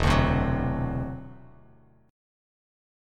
E7sus2#5 chord